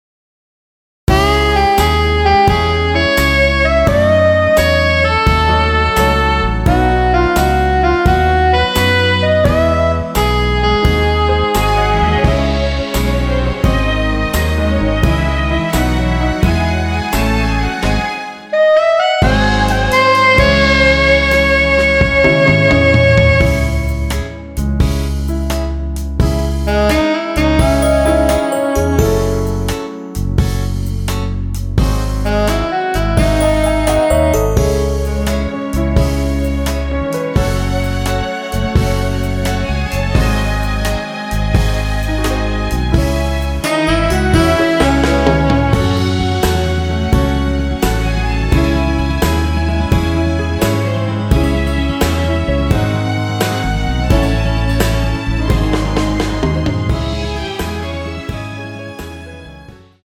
원키에서(-2)내린 MR입니다.
Dbm
앞부분30초, 뒷부분30초씩 편집해서 올려 드리고 있습니다.
중간에 음이 끈어지고 다시 나오는 이유는